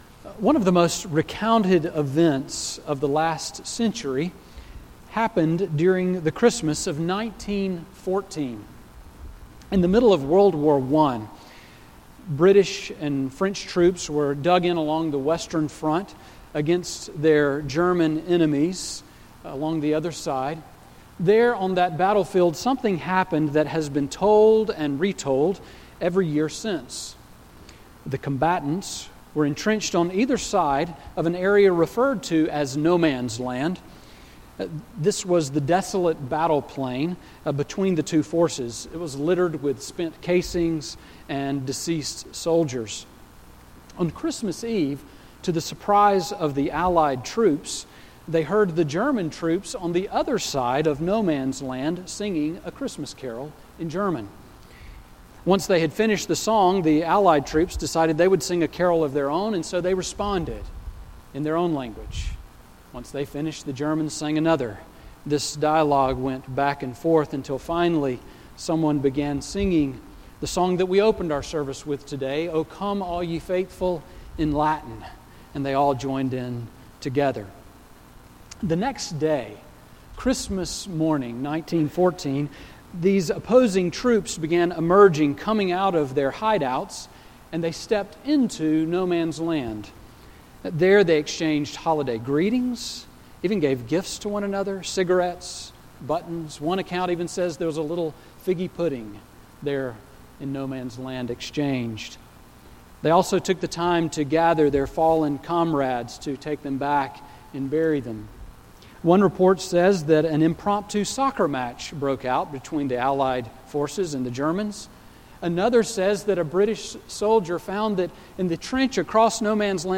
Sermon on Micah 6:1-8 from December 20